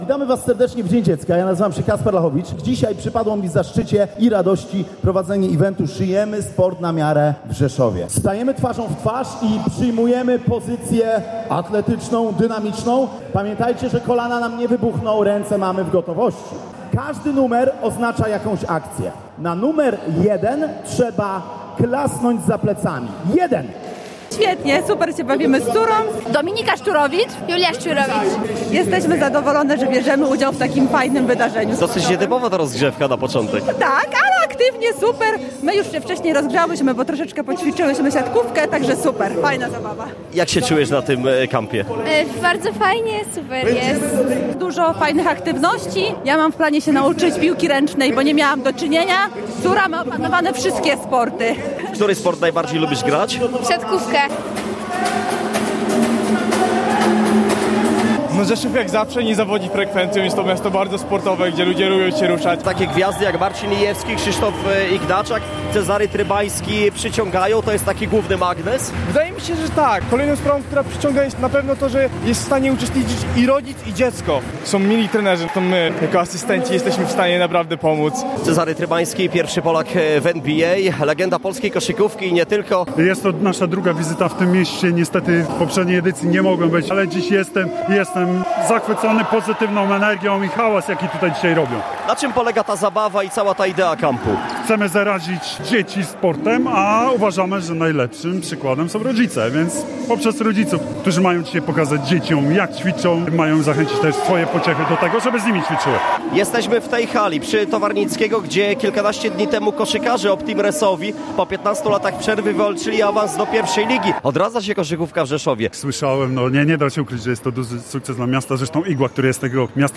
Sportowy Camp. Relacja z wydarzenia